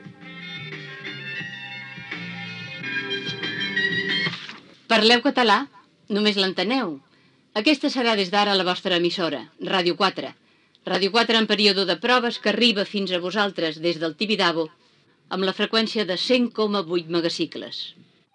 Identificació de l'emissora i freqüència en període de proves